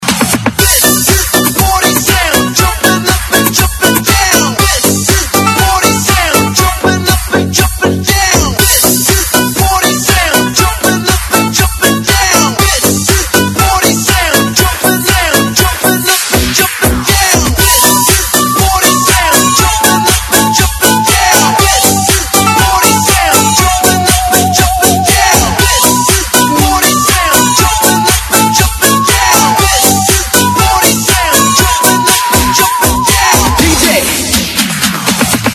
分类: DJ铃声
特效人声